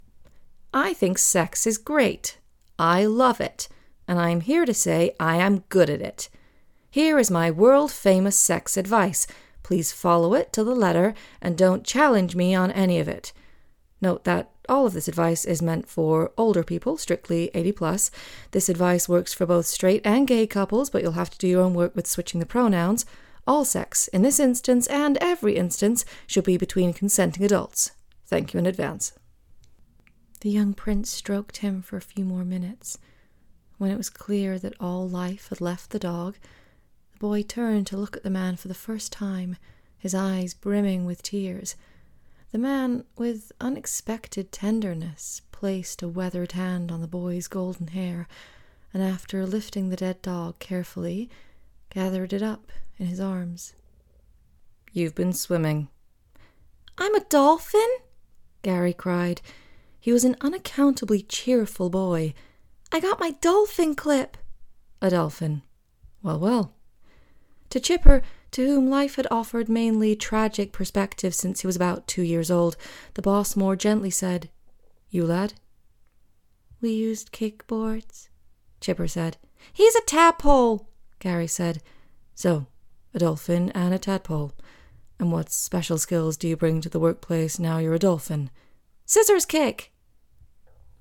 US Reel
• Home Studio
With an instinctive grasp of character, she too has a talent for the off-beat and the quirky.